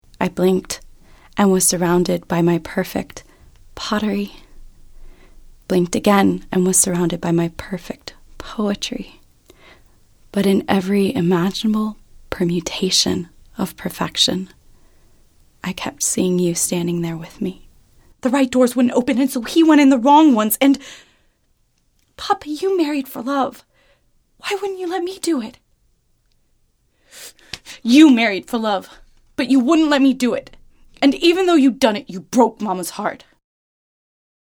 Female
Bright, Friendly, Natural, Warm, Young
American Standard, American Southern, California, New York
Fun, youthful, natural and real, can also be strong, confident and professional.
Typically cast as: friendly & conversational, trusted professional, young mom, savvy entrepreneur, hip social media influencer.
Microphone: AudioTechnica 2020